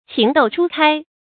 情竇初開 注音： ㄑㄧㄥˊ ㄉㄡˋ ㄔㄨ ㄎㄞ 讀音讀法： 意思解釋： 指剛剛懂得愛情（多指少女）。